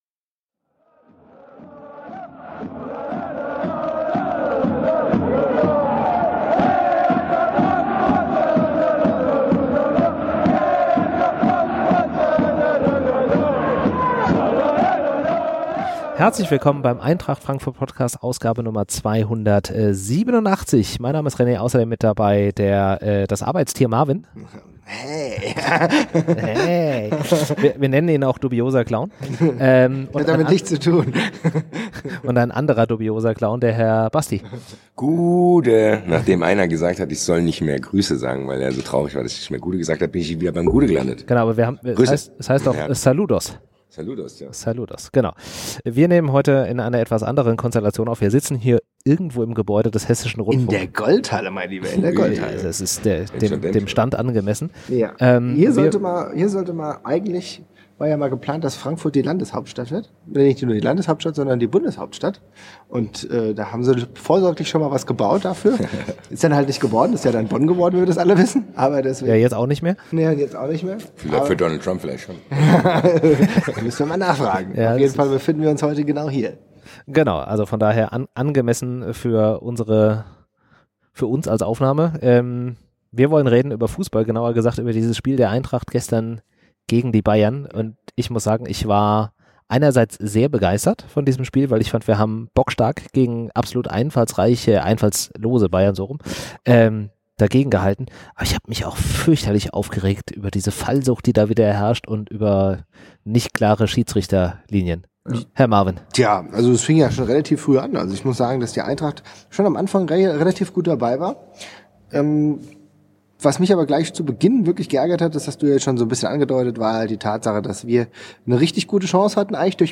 Wir nehmen zu kurz nach dem Spiel auf. Deswegen wird es durchaus etwas emotional.
Live aus der goldenen Halle der Hessischen Rundfunk